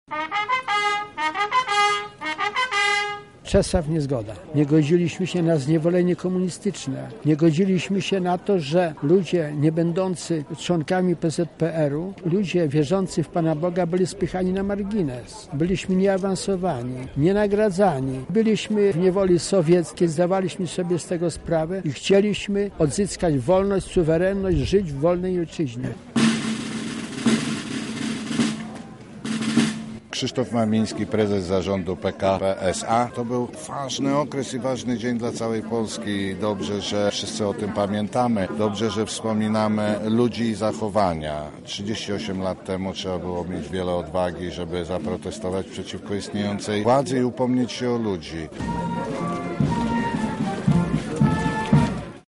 Na miejscu wydarzenia był nasz reporter.